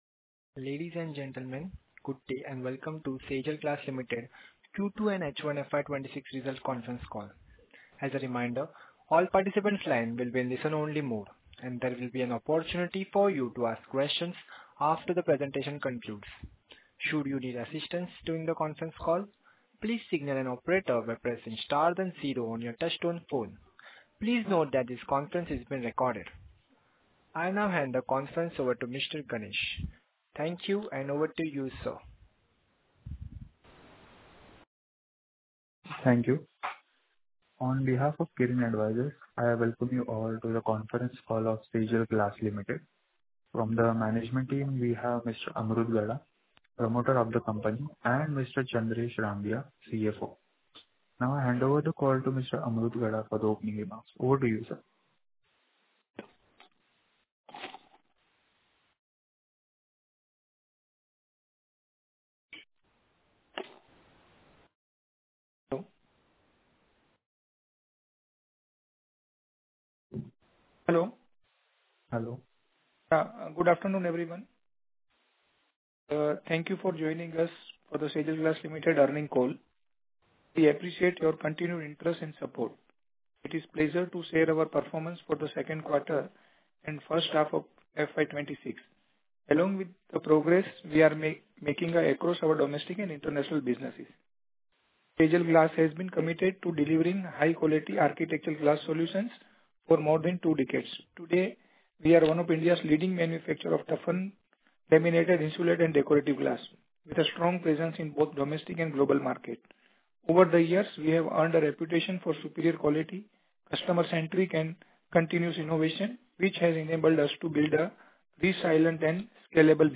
Concalls
SGL Q2-H1 FY26 conference call.mp3